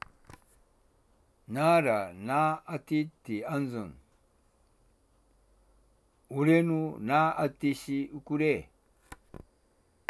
ッショー-ッショー [çi̥ʃoːçi̥ʃoː]（連）